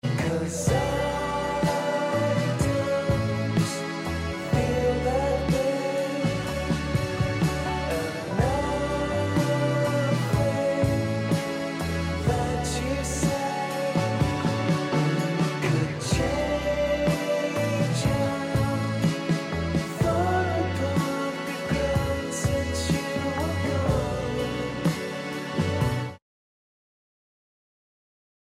psychedelic pop rock